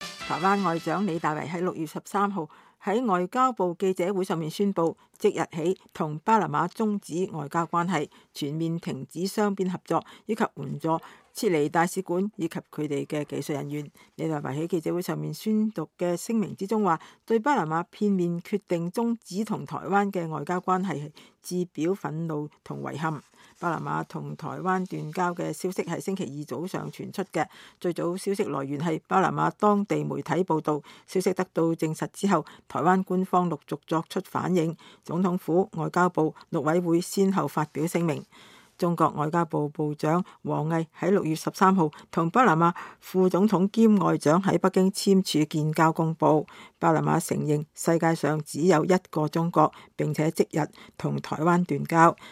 台灣外長李大維在6月13日的記者會上宣佈與巴拿馬終止外交關係。